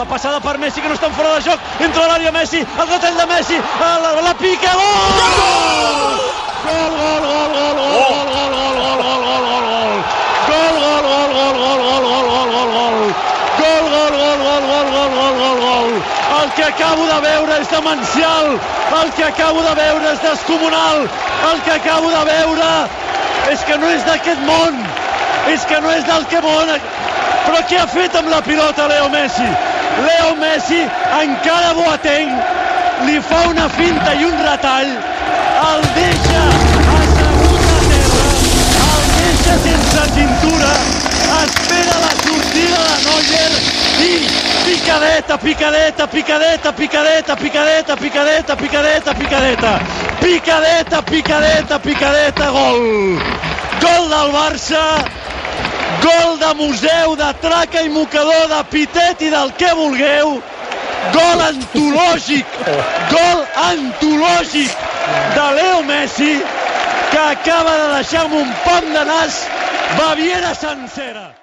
Narració des del Camp Nou del gol de Leo Messi al partit d'anada de la semifinal de la Copa d'Europa de Futbol masculí entre el Futbol Club Barcelona Bayern Munich (El partit va acabar 3 a 0)
Esportiu